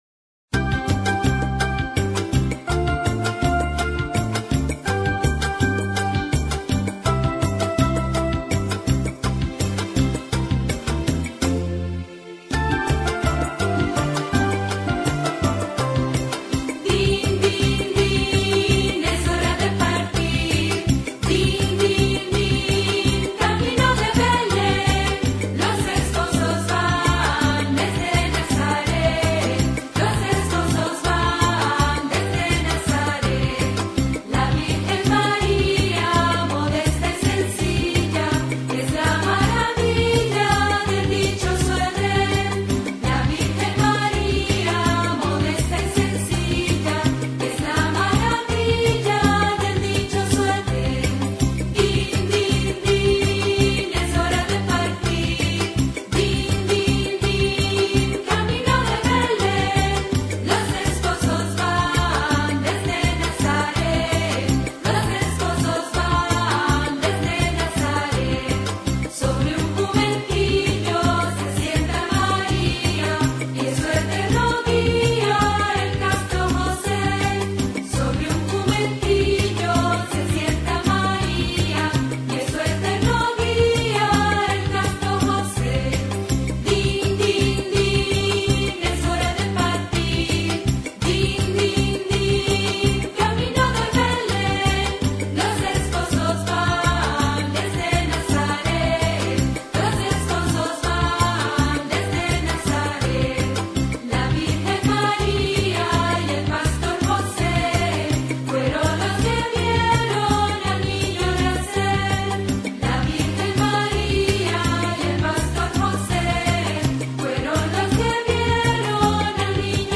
Volver a Podcast Ver todo Din Din Din on 2008-12-25 - Villancicos Descargar Otros archivos en esta entrada Nuestras Creencias Las creencias adventistas tienen el propósito de impregnar toda la vida.